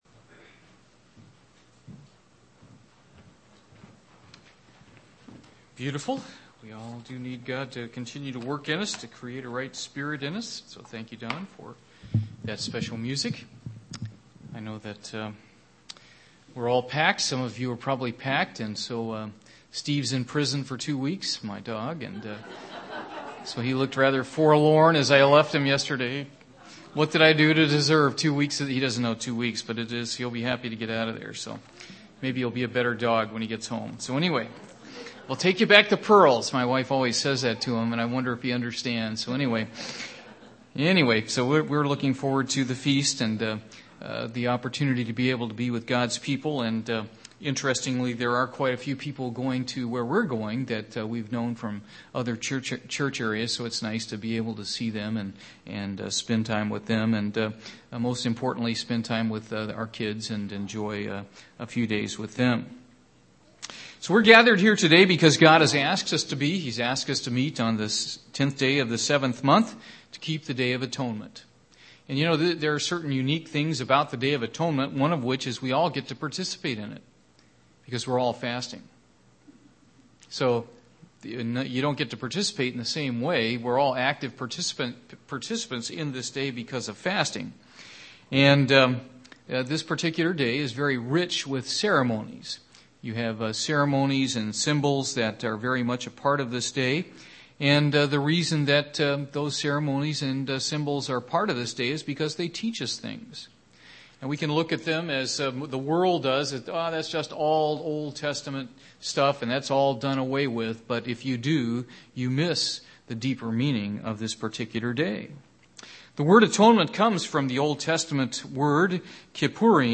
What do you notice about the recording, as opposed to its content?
Given in Portsmouth, OH